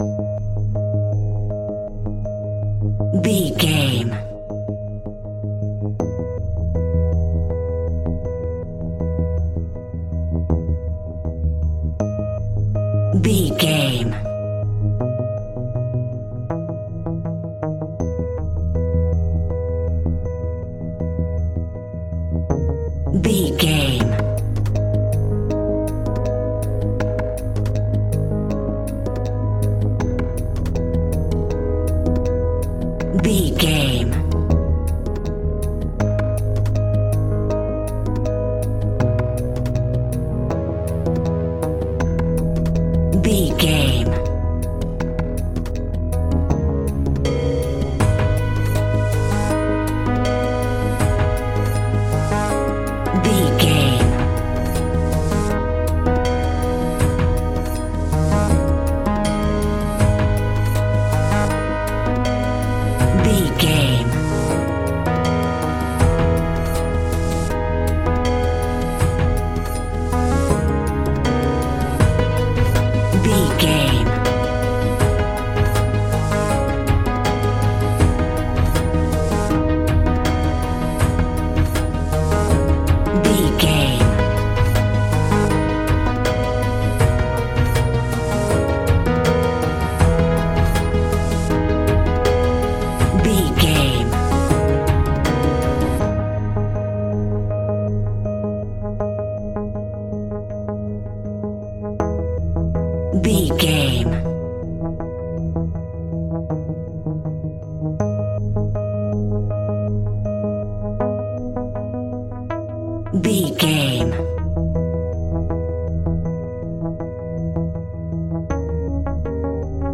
Aeolian/Minor
G#
ominous
dark
haunting
eerie
electric piano
percussion
drums
synthesiser
strings
horror music